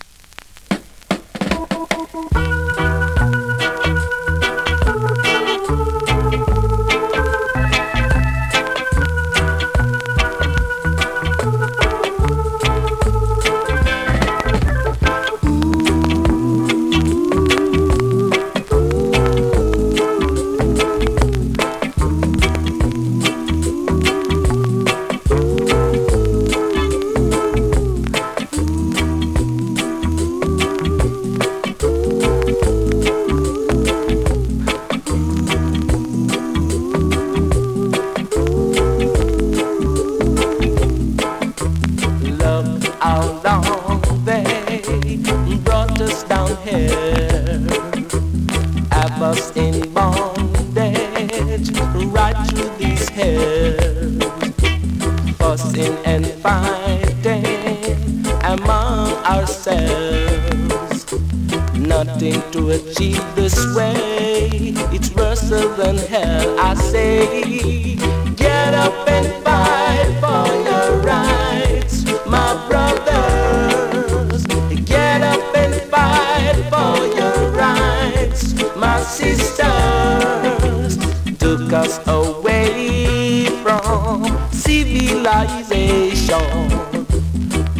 2023 NEW IN!!SKA〜REGGAE!!
スリキズ、ノイズ比較的少なめで